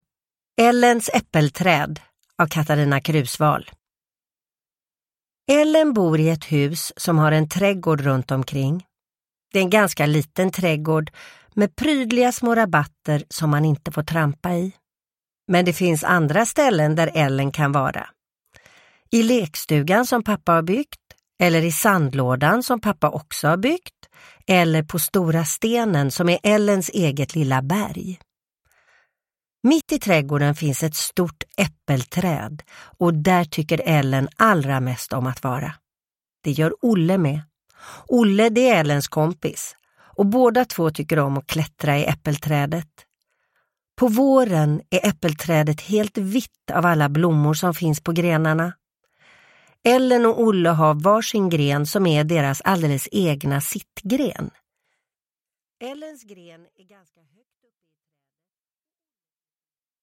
Ellens äppelträd – Ljudbok – Laddas ner
Uppläsare: Sissela Kyle